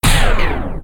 digging.ogg